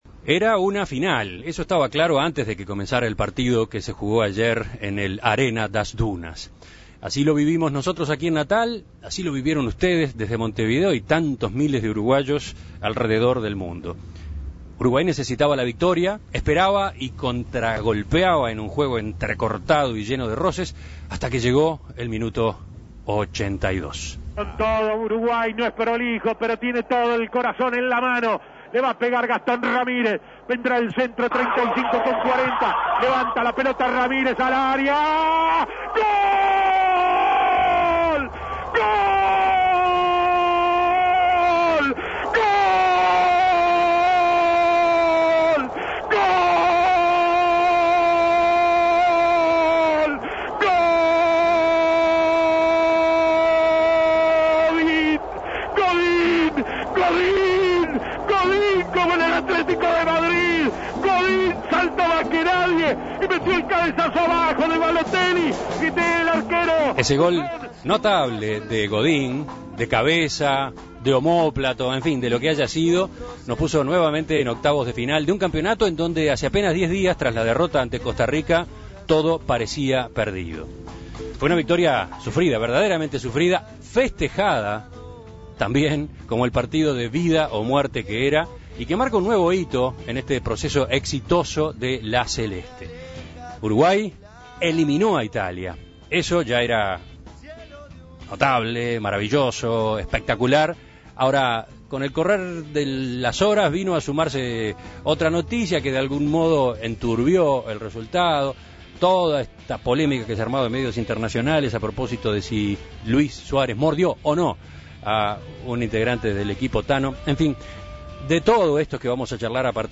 Uno de los balcones del hotel donde se hizo la tertulia especial